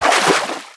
3098b9f051 Divergent / mods / Soundscape Overhaul / gamedata / sounds / material / human / step / water02gr.ogg 8.6 KiB (Stored with Git LFS) Raw History